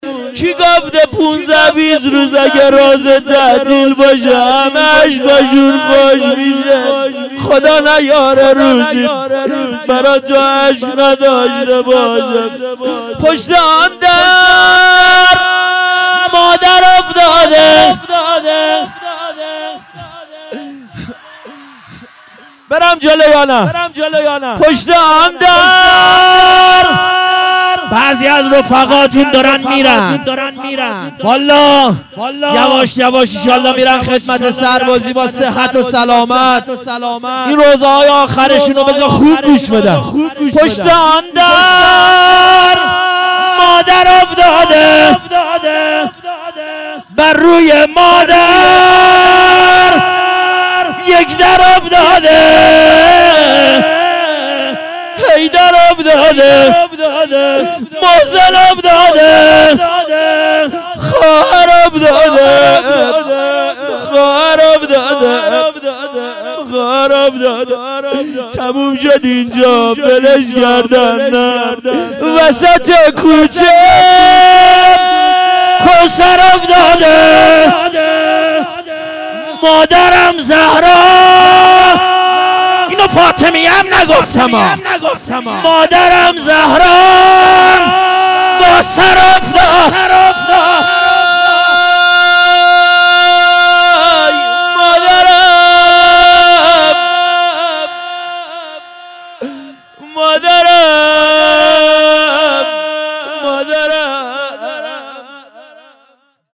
روضه-کی-گفته.mp3